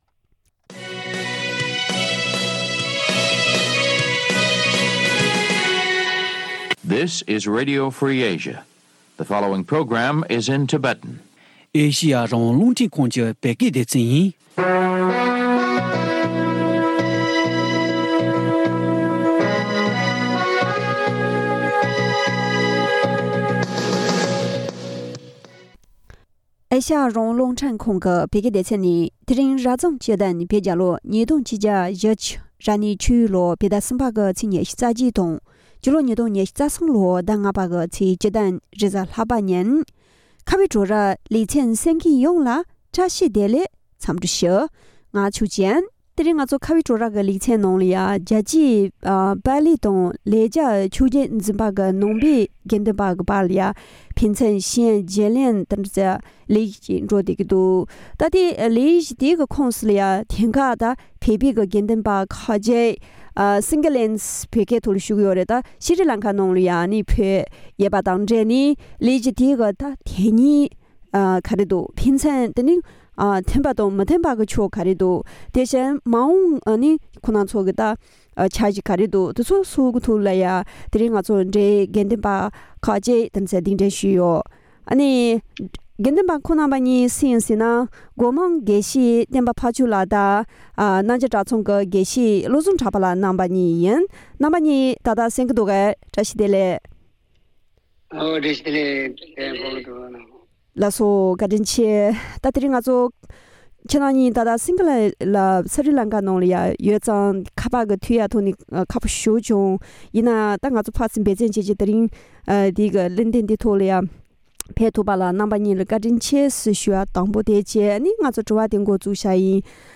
བགྲོ་གླེང་ཞུས་པར་གསན་རོགས་ཞུ།།